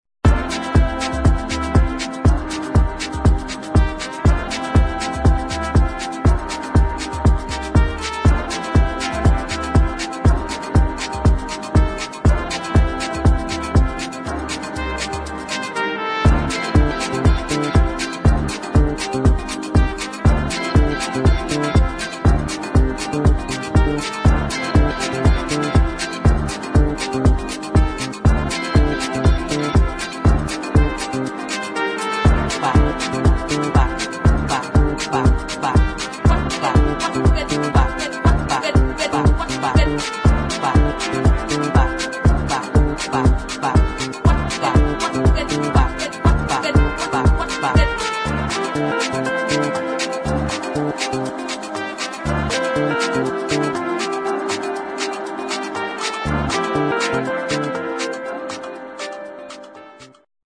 [ AFRO FUNK | HOUSE | BREAKBEAT ]